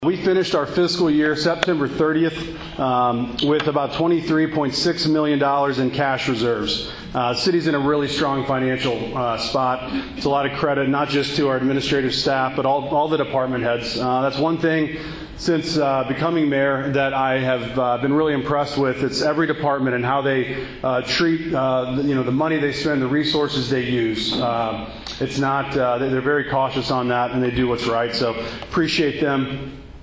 Farmington, Mo. (KFMO) - Farmington Mayor Chris Morrison delivered the annual State of the City address Thursday during the Farmington Regional Chamber of Commerce Business and Community Luncheon.